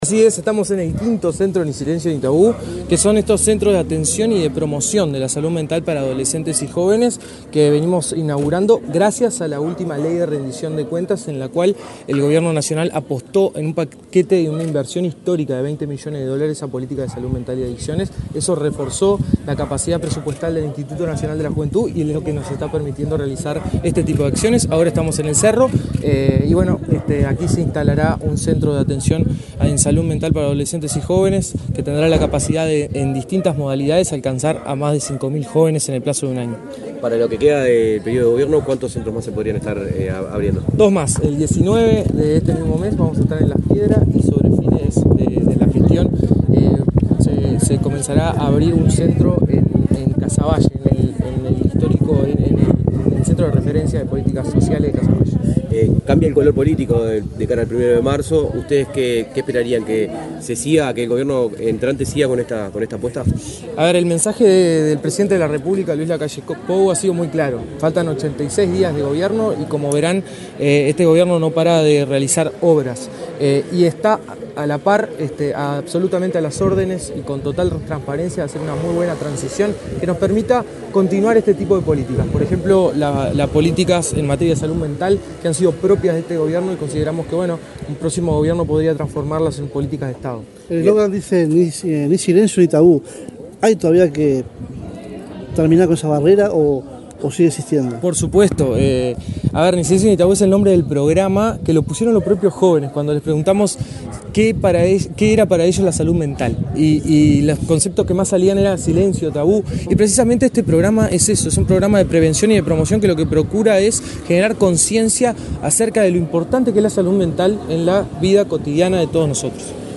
Declaraciones del director del INJU, Aparicio Saravia
El director del Instituto Nacional de la Juventud (INJU), Aparicio Saravia, dialogó con la prensa, antes de participar en la inauguración del centro